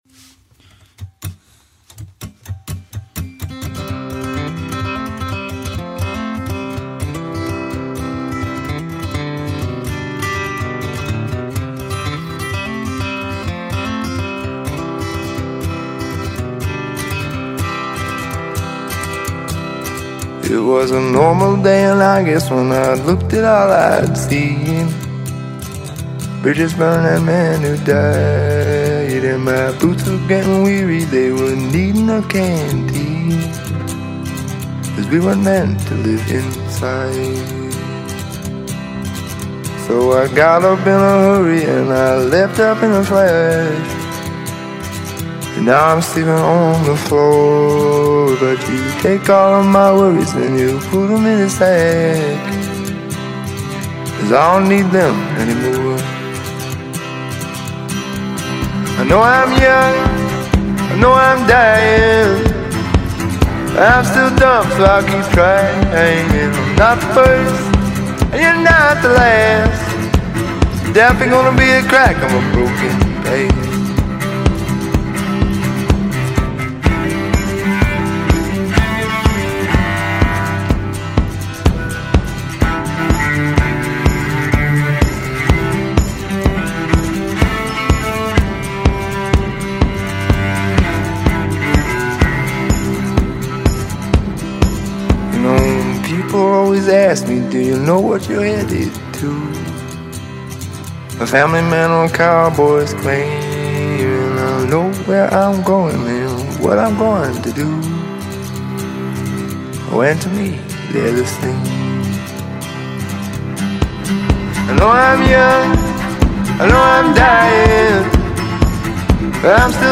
an Indie Folk duo based out of Montréal
guitarist
cellist